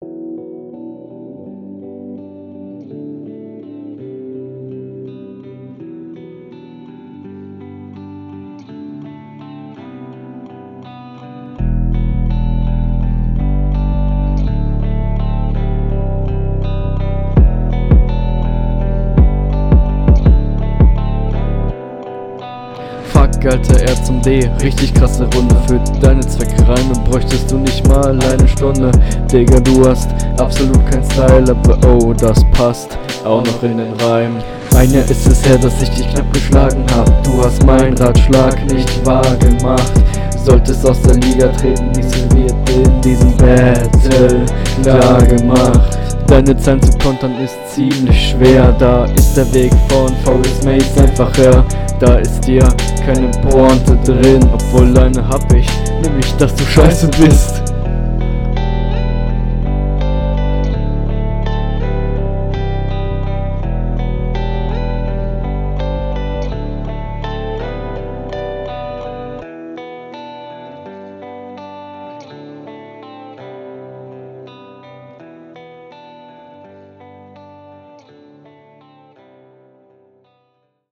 Der Flow hat gute Ansätze. Es ist allerdings alles sehr drucklos eingerappt.